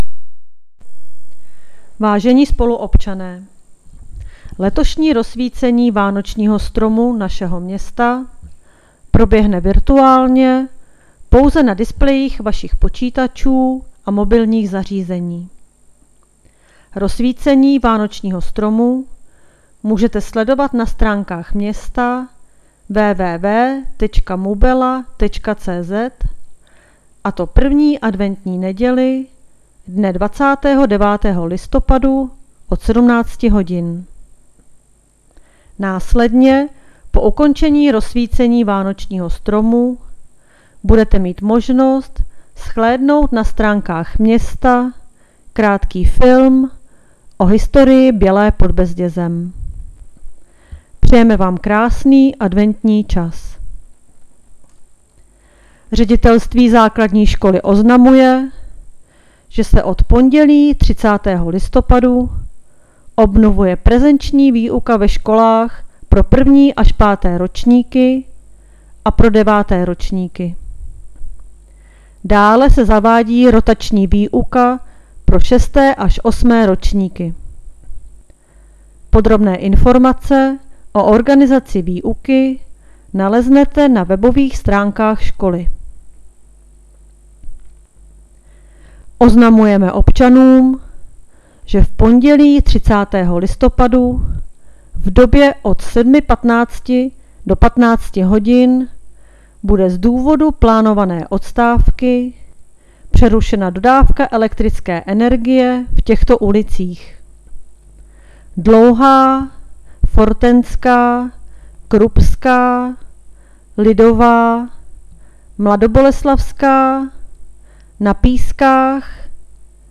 Hlášení městského rozhlasu dne 27.11.2020